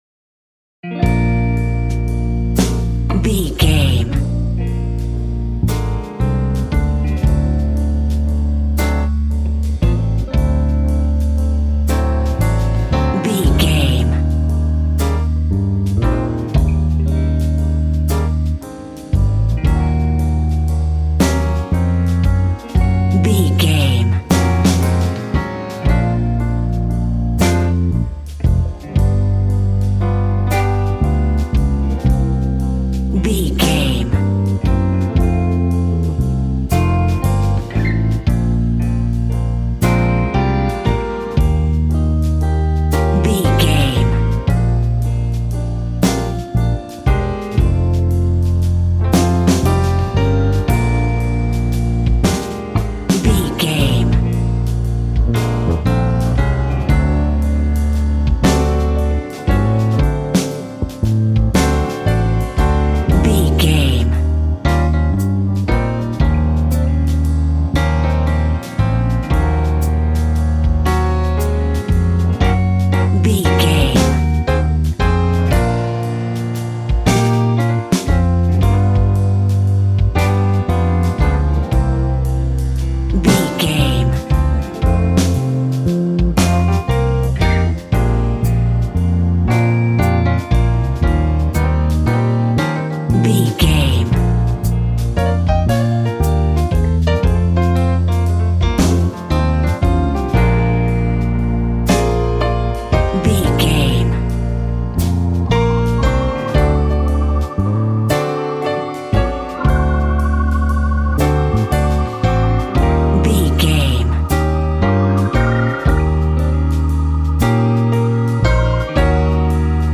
Ionian/Major
B♭
sad
mournful
bass guitar
electric guitar
electric organ
drums